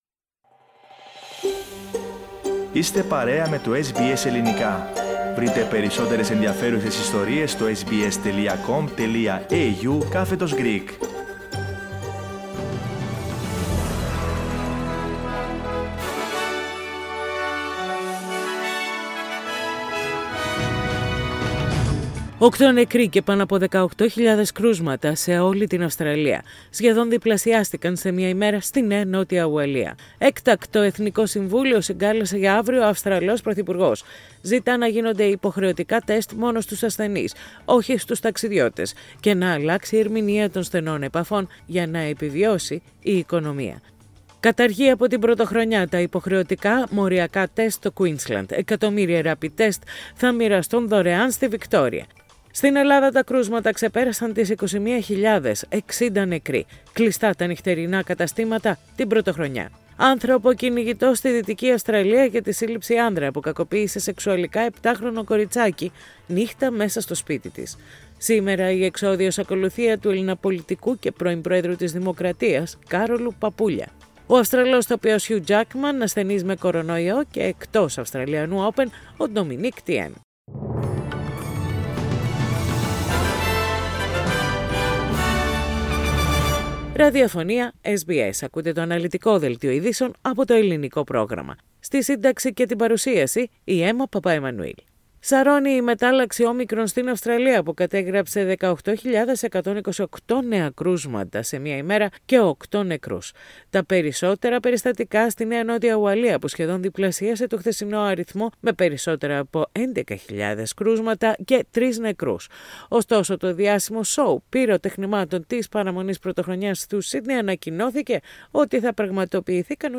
The detailed bulletin in Greek, with the main news of the day, from Australia, Greece, Cyprus and the international arena.